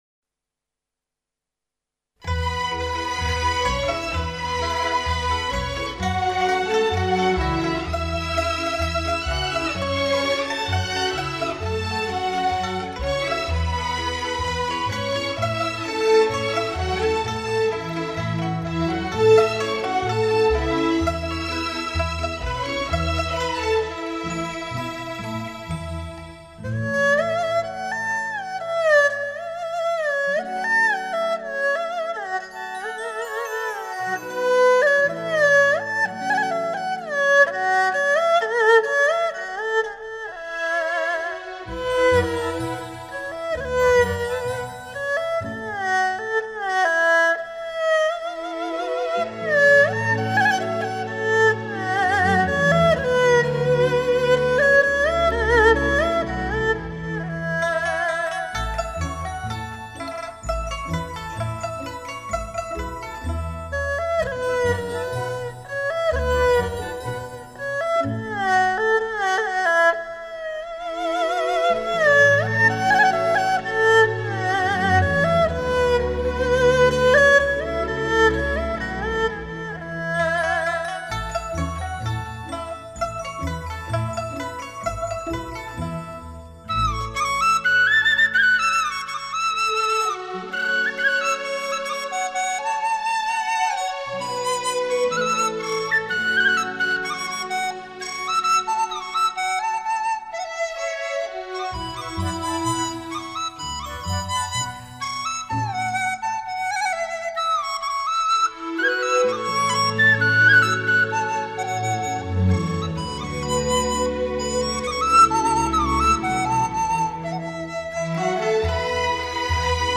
以中国民族乐器为主奏、领奏，辅以西洋管弦乐器及电声乐器。
最新数码系统录制，值得您永远珍藏。